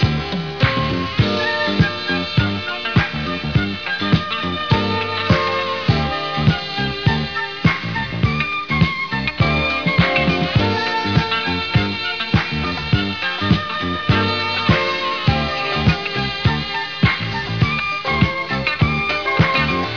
(cooldown)  [ 211 KB ]